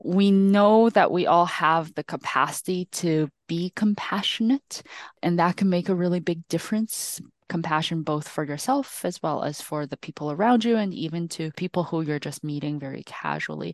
Medical Officer of Health, Dr. Linna Li says this year’s Mental Health Week centers around the healing power of compassion.